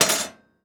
metal_object_small_move_impact_01.wav